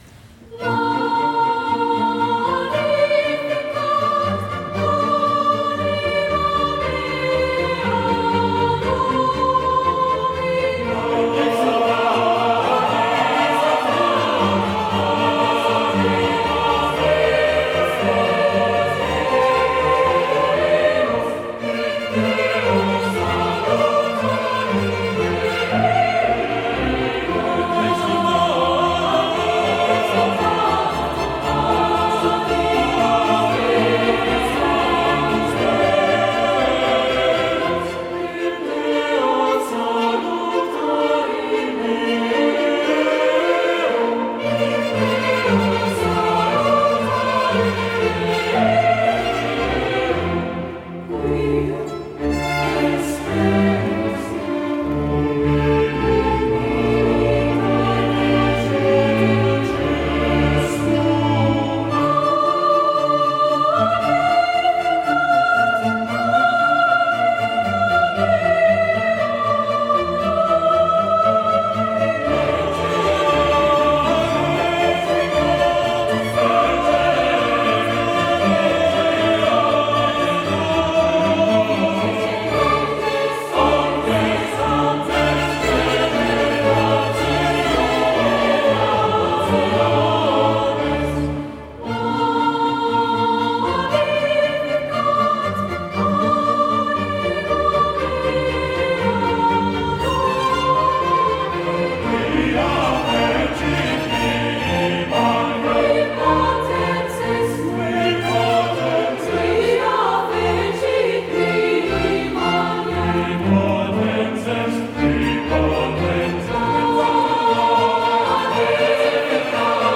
Répétition SATB par voix sur le site  (lien externe)
Tutti
Mix 4 voix.mp3